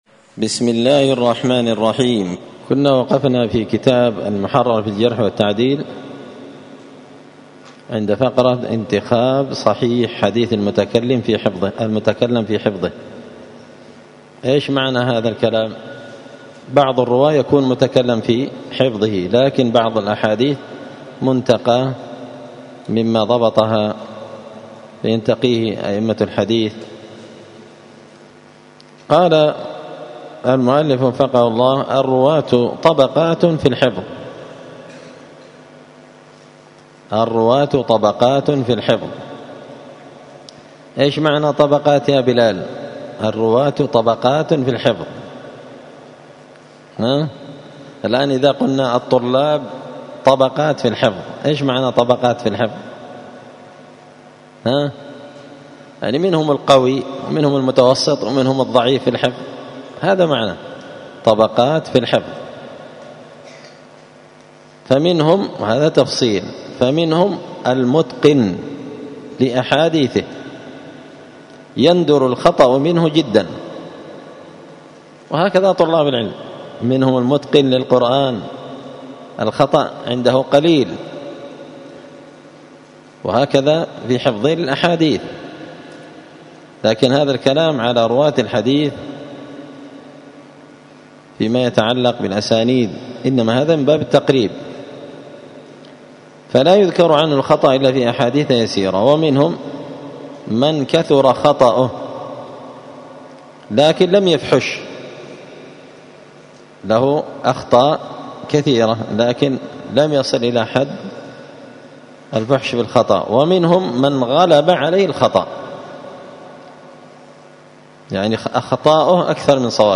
*الدرس الثامن والثلاثون (38) باب انتخاب صحيح حديث المتكلم في حفظه*